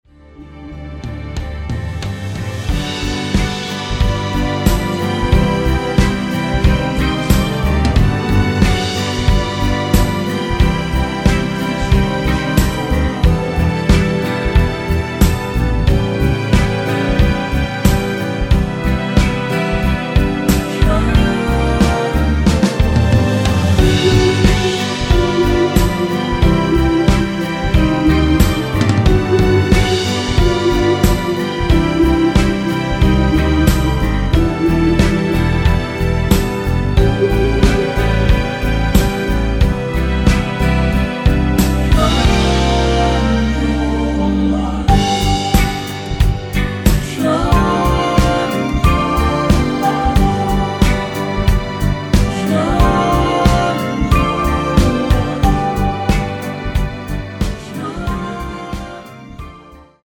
원키에서(-3)내린 코러스 포함된 MR입니다.(미리듣기 참조)
앞부분30초, 뒷부분30초씩 편집해서 올려 드리고 있습니다.